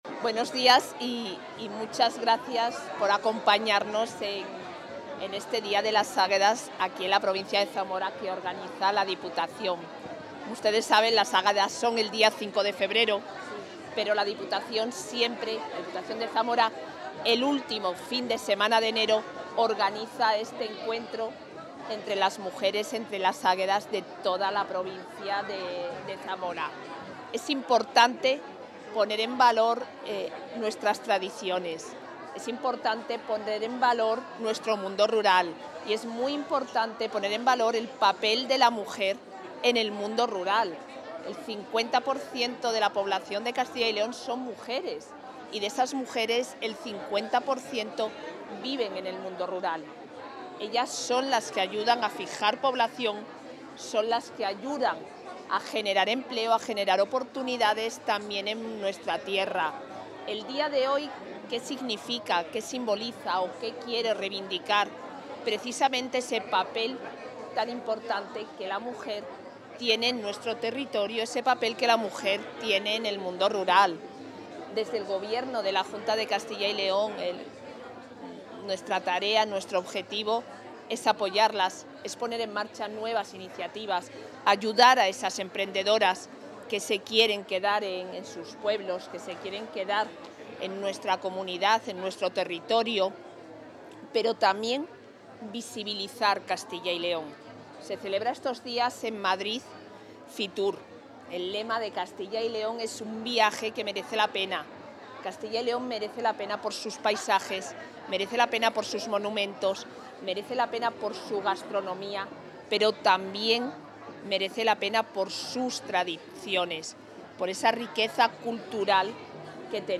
Declaraciones de la vicepresidenta.
La vicepresidenta de la Junta de CyL y consejera de Familia e Igualdad de Oportunidades, Isabel Blanco, ha participado en el VIII Encuentro Provincial de Águedas, celebrado en la localidad zamorana de Muelas del Pan.